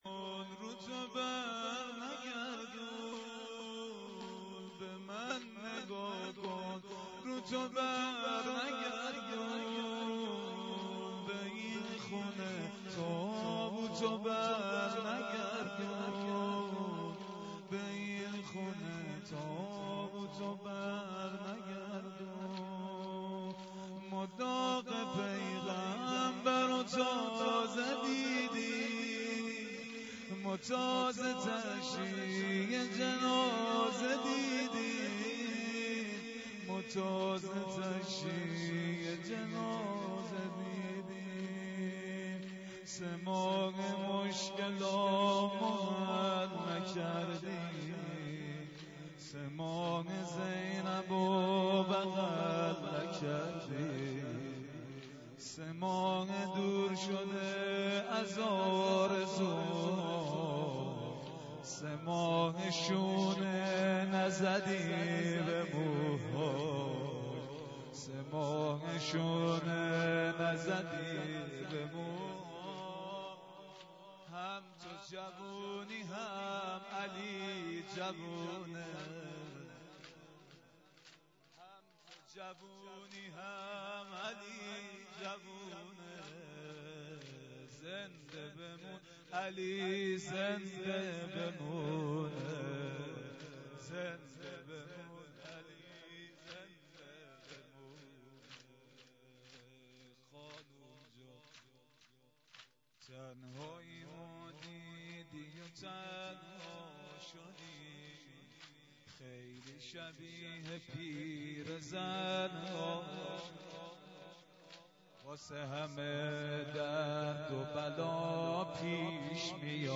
مراسم هیئت سپهسالار تهران
مداحی